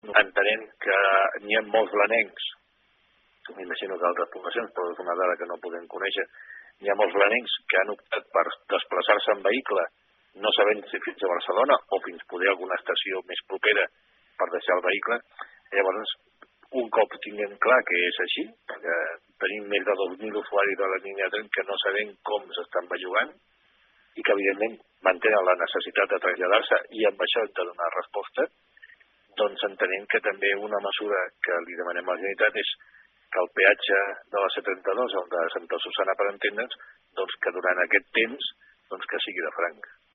Canosa ho ha dit en declaracions a RP.